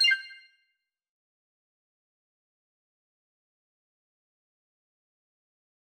confirm_style_4_007.wav